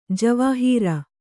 ♪ javāhīra